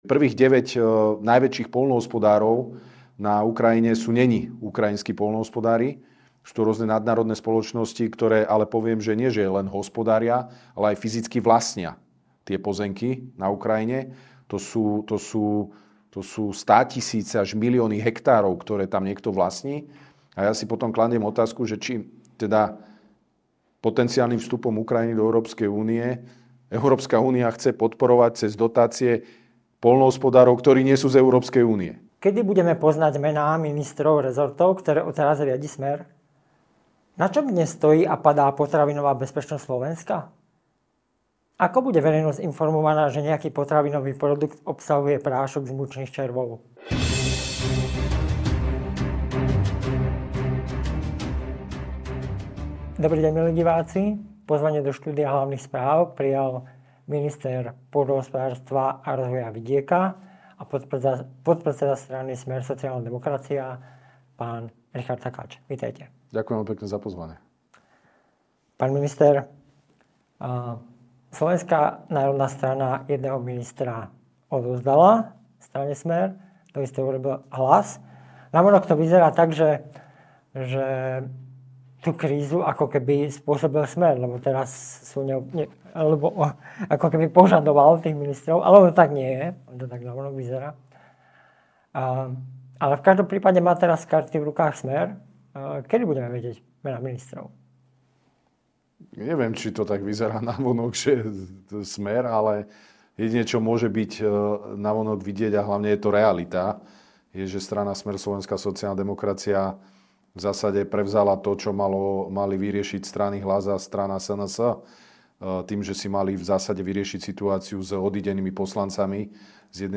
Pozvanie do štúdia Hlavných Správ prijal minister pôdohospodárstva a rozvoja vidieka SR a podpredseda strany Smer-SSD, Ing. Richard Takáč.